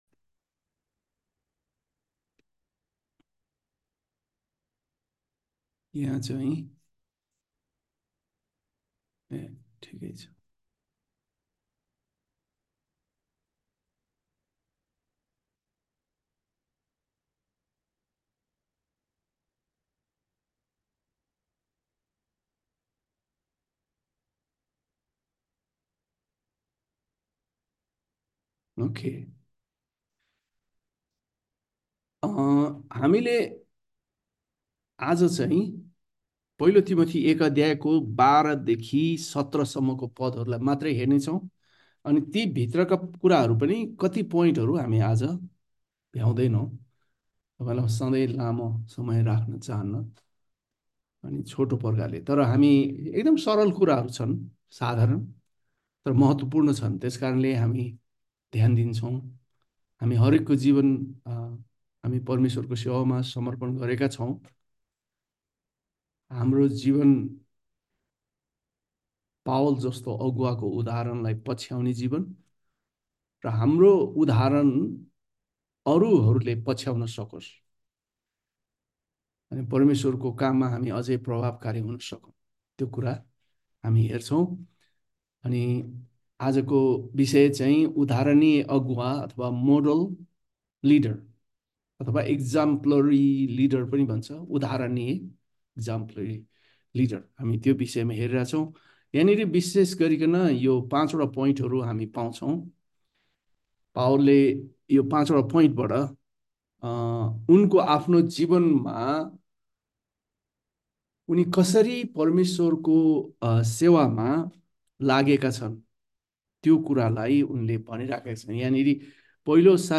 Nepali Sermon उधारणीय अगुवा Model leader-LT Play Episode Pause Episode Mute/Unmute Episode Rewind 10 Seconds 1x Fast Forward 30 seconds 00:00 / 51:06 Subscribe Share RSS Feed Share Link Embed